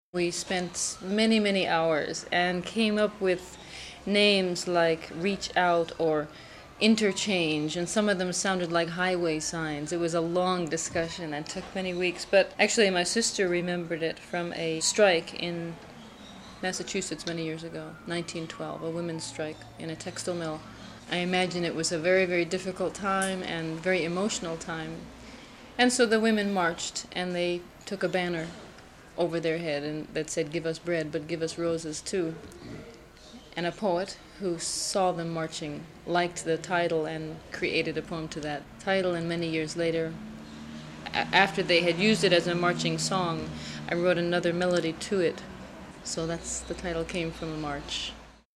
INTERVIEWS WITH MIMI:
for German public radio